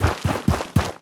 biter-walk-big-10.ogg